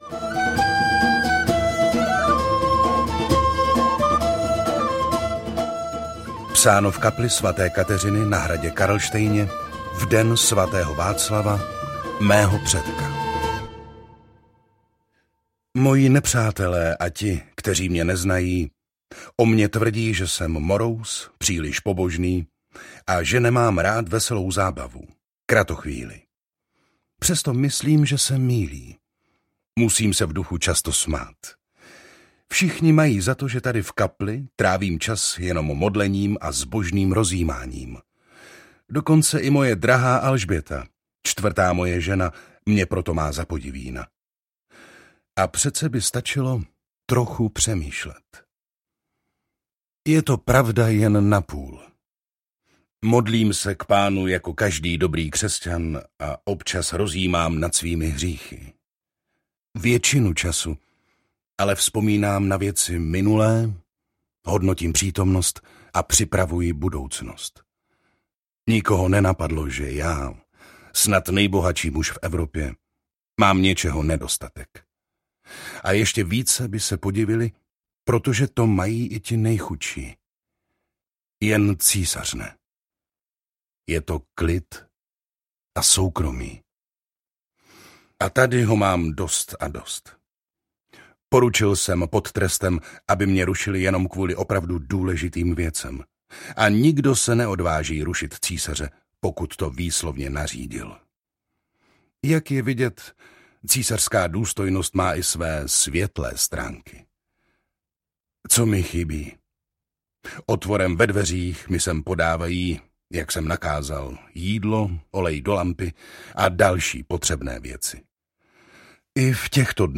Karel IV. - Tajný deník audiokniha
Ukázka z knihy
• InterpretJiří Dvořák